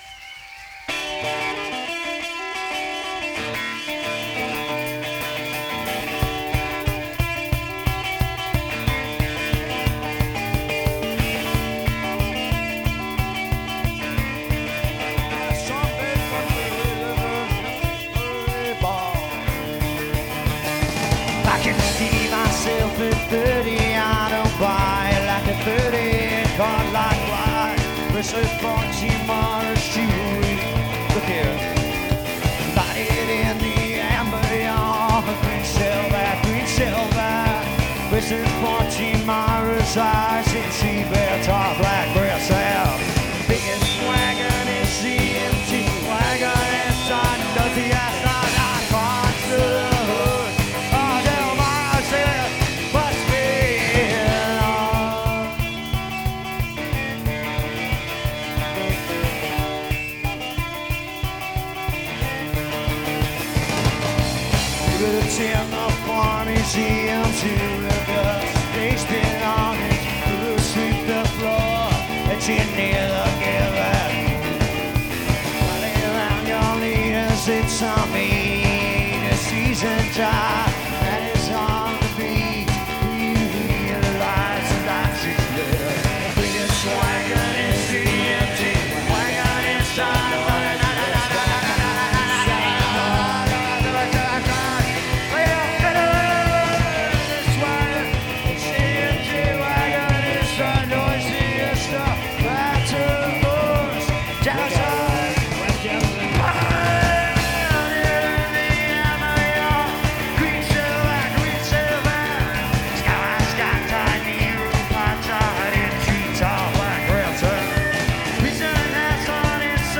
This review is on the soundboard show.